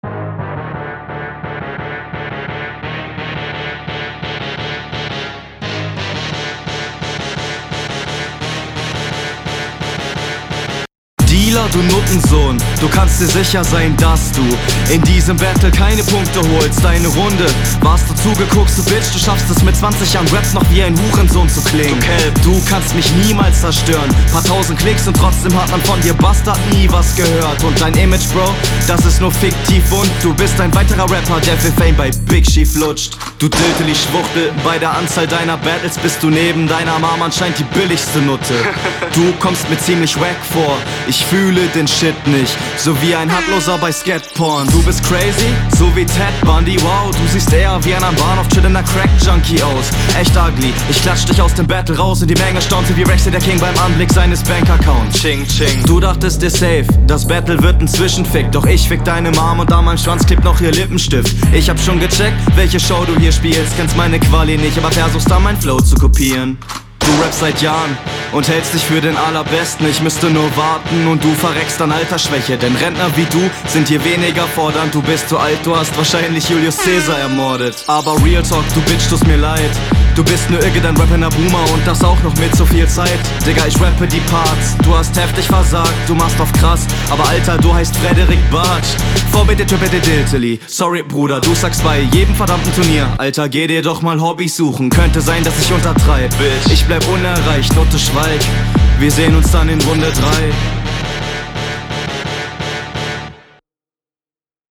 Schrecklicher Beat. 20 Jahre rap und wie´n Hurensohn klingen stumpf aber witzig.